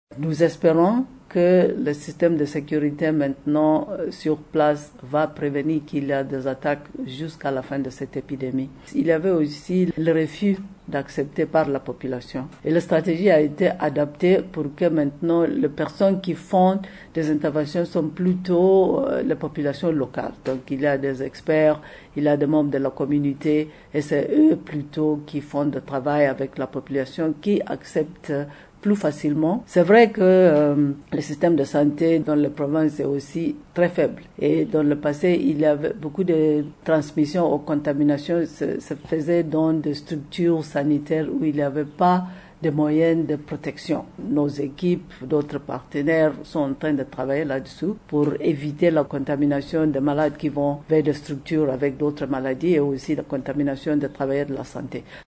Des avancées notables ont été enregistrées dans la lutte contre la maladie à virus Ebola en RDC, a affirmé samedi 15 février la directrice régionale de l’OMS pour l’Afrique, Dr Matshidiso Moeti. C’était au cours d’une conférence de presse tenue à Kinshasa.